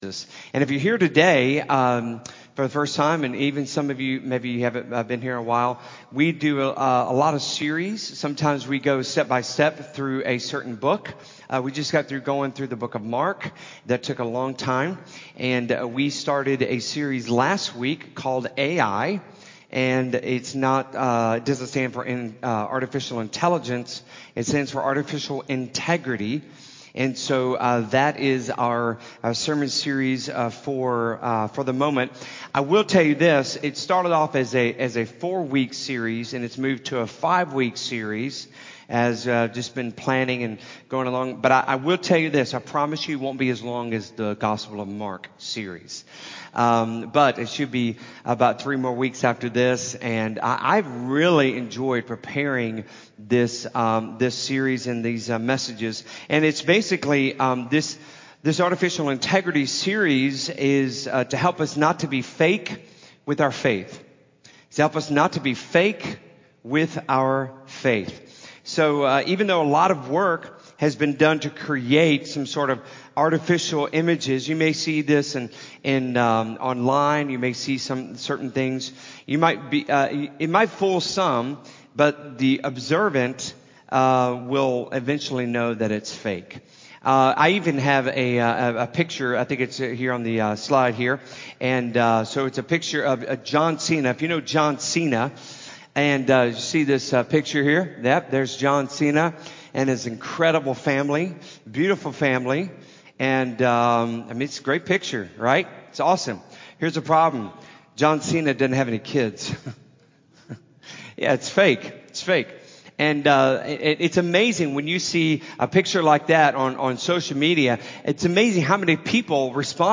August-24-Sermon-Audio-CD.mp3